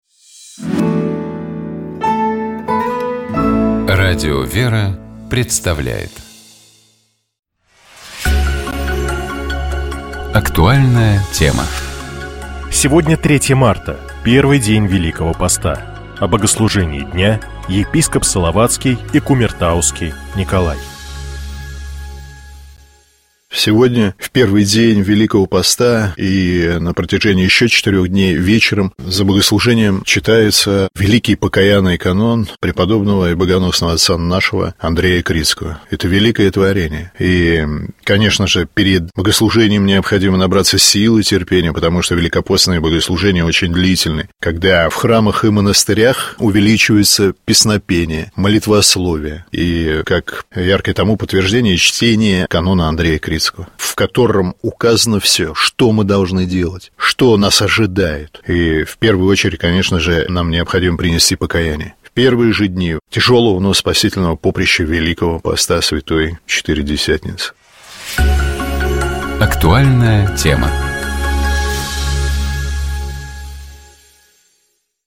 О богослужении дня — епископ Салаватский и Кумертауский Николай.